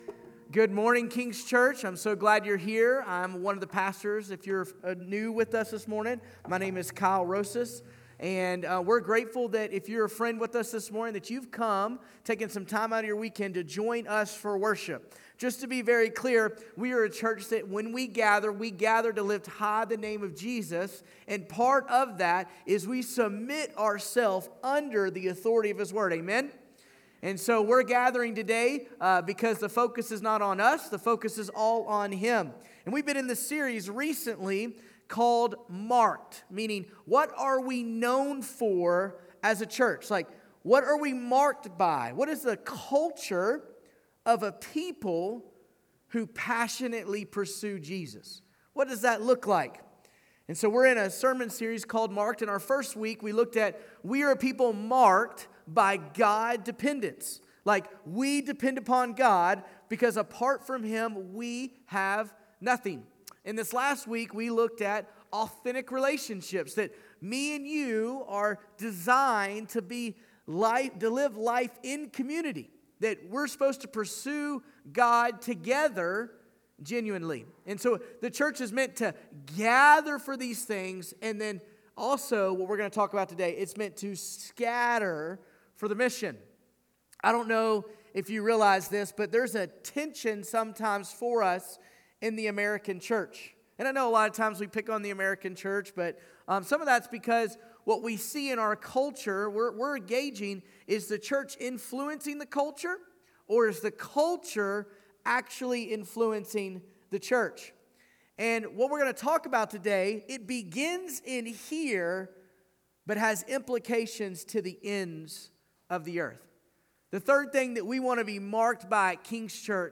Jan18Sermon.mp3